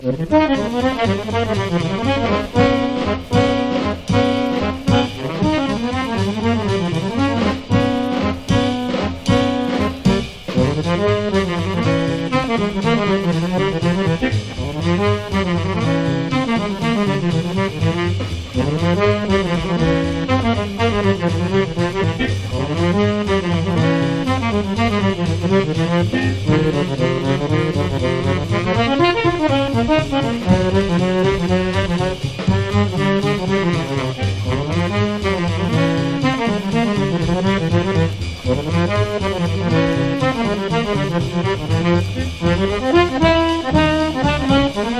バランス良く軽妙洒脱なジャズを展開。
Jazz　USA　12inchレコード　33rpm　Mono